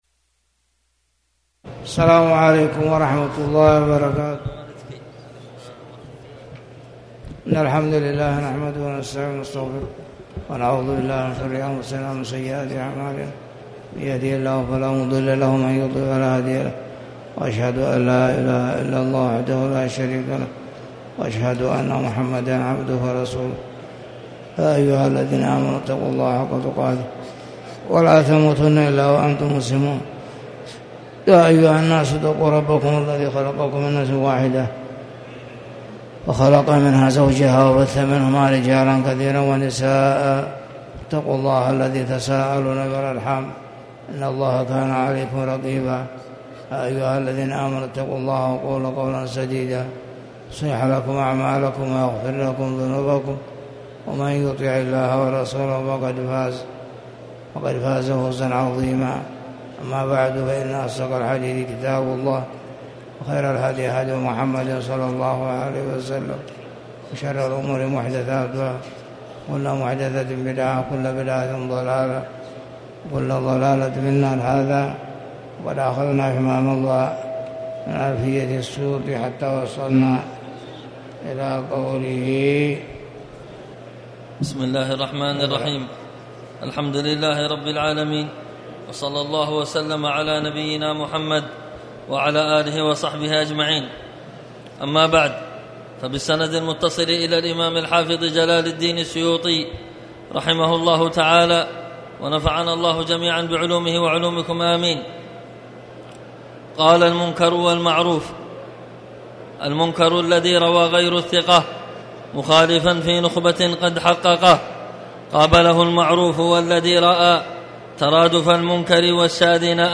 تاريخ النشر ٧ محرم ١٤٤٠ هـ المكان: المسجد الحرام الشيخ